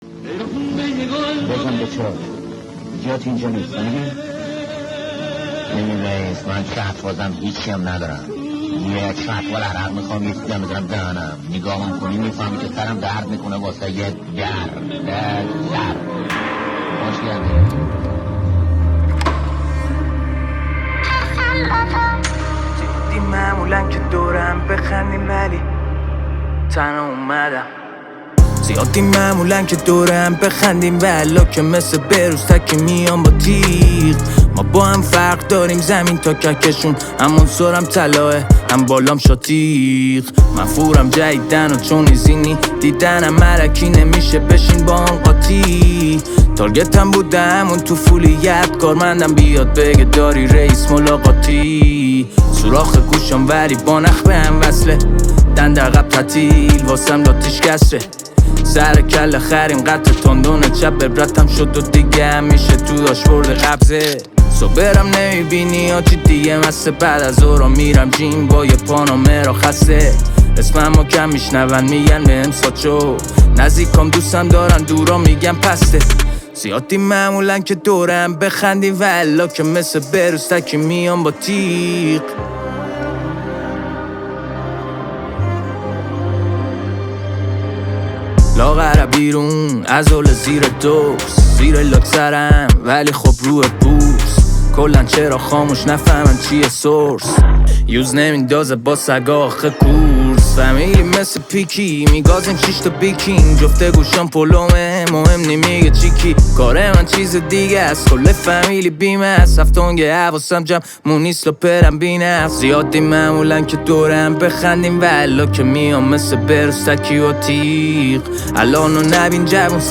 • موسیقی رپ ایرانی